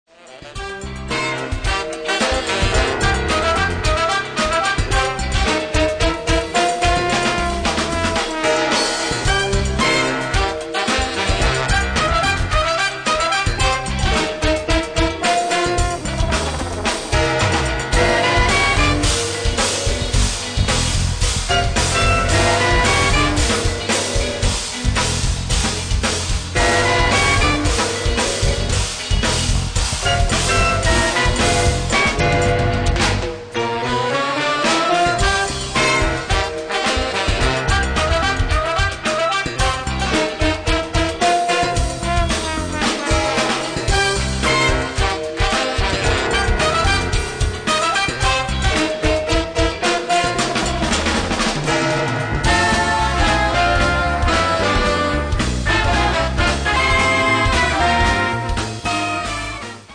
trumpets
trombone
tuba
flute
tenor sax
baritone sax
vibes
guitar
keyboards
piano
bass guitar
drums
percussion